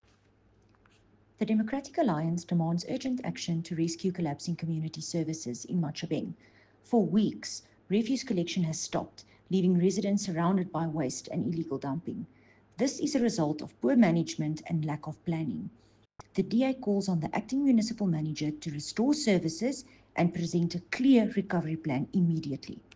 Afrikaans soundbites by Cllr René Steyn and